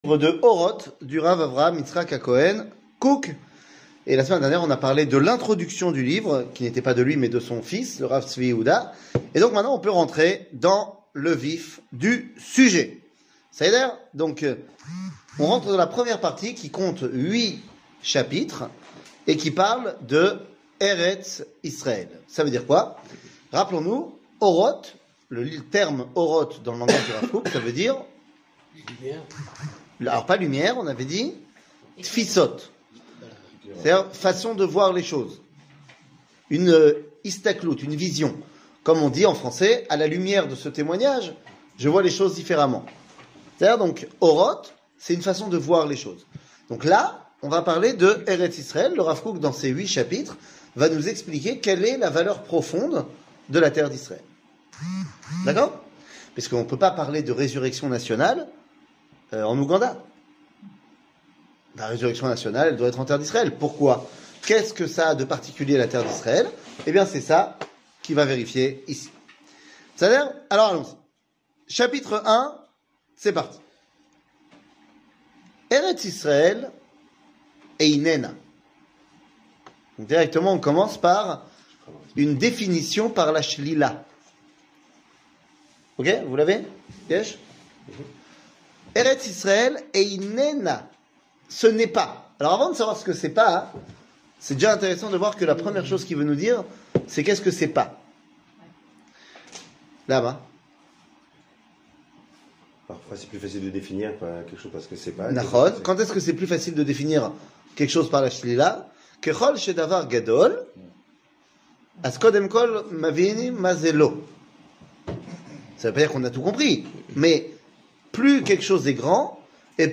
Orot du Rav Look, Eretz Israel, chapitre 1 שיעור מ 23 ינואר 2023 52MIN הורדה בקובץ אודיו MP3 (47.89 Mo) הורדה בקובץ וידאו MP4 (107.1 Mo) TAGS : שיעורים קצרים